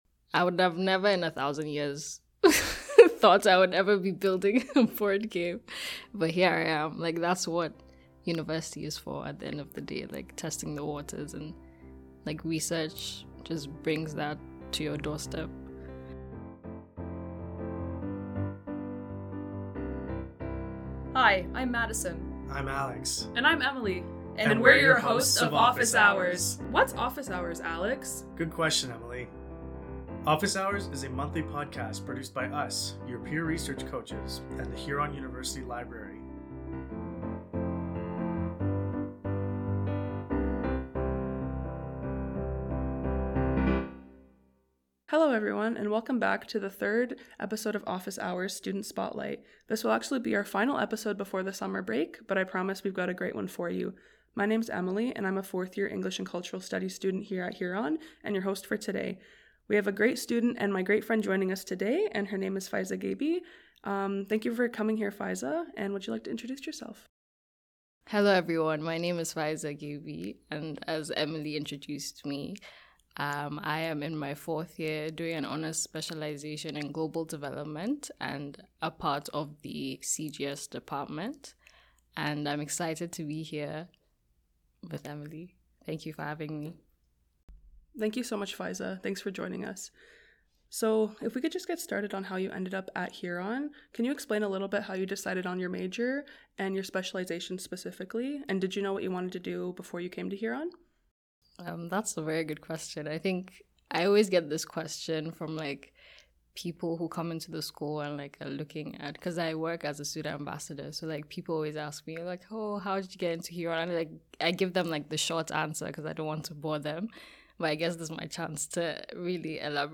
A Conversation
SSEP3 Guest